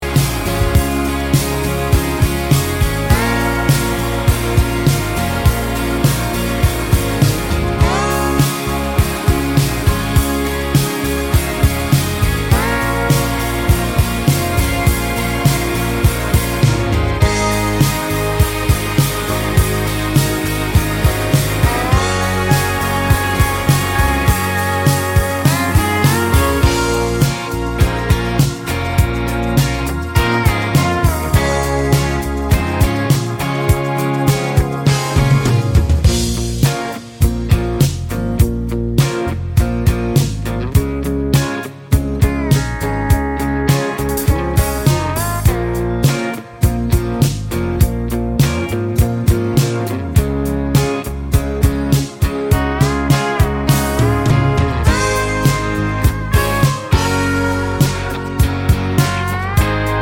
no Backing Vocals Country (Male) 3:40 Buy £1.50